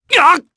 Gladi-Vox_Damage_jp_02.wav